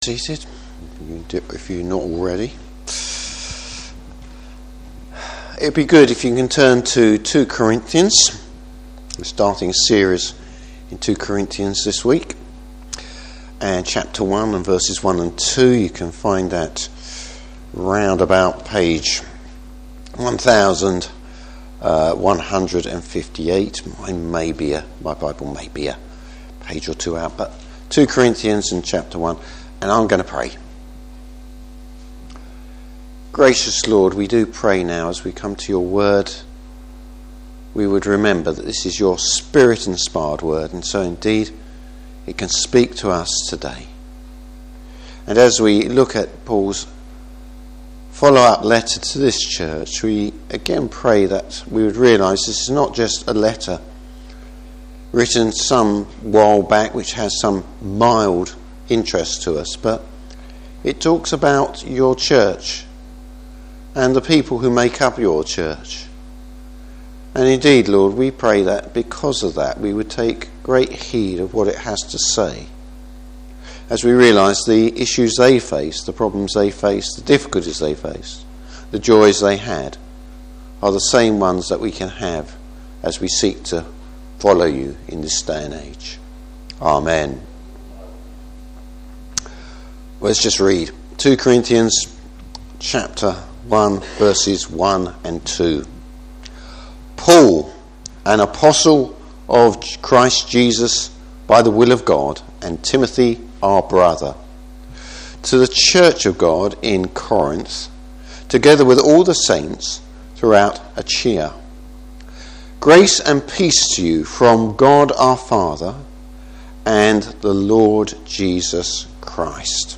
Service Type: Morning Service How does Paul introduce the Letter?